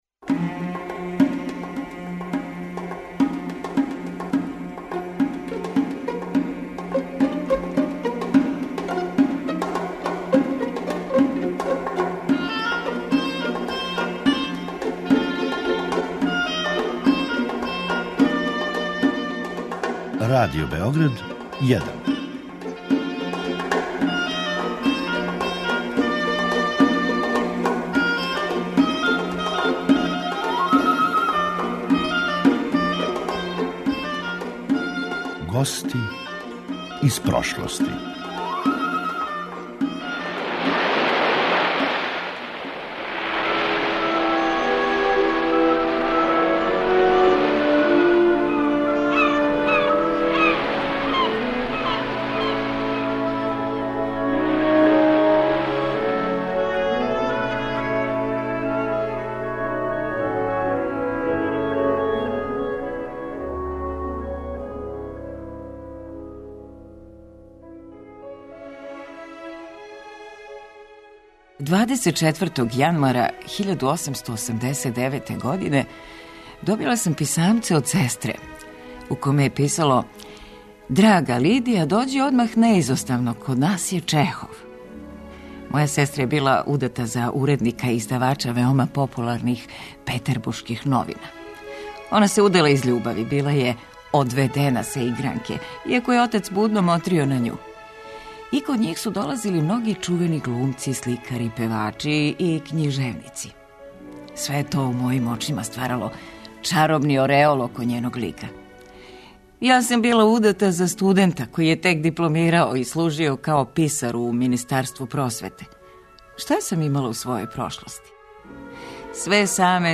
У „Гостима из прошлости" - драма о сусретима Лидије Авилове и Антона Павловича Чехова чије су се судбине преплитале још неколико пута, све до премијере „Галеба"....